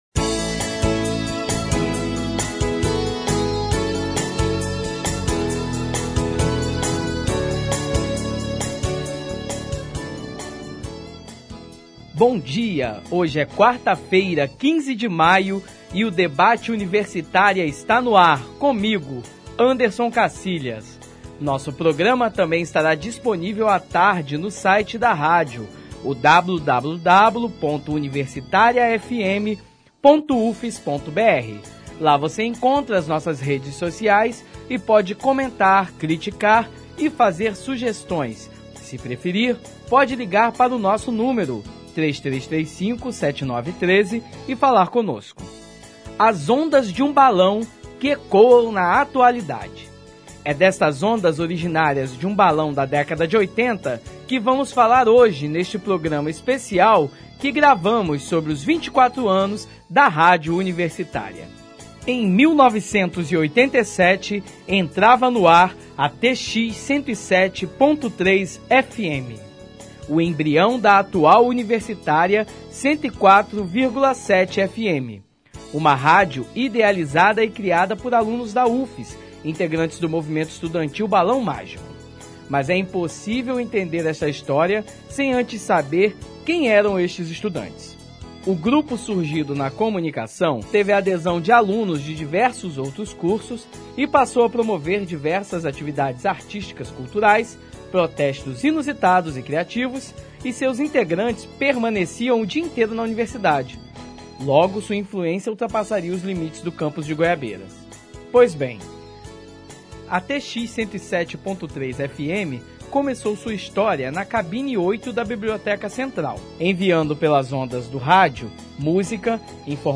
Ouça o debate: Debate Universitária especial Download : Debate Universitária especial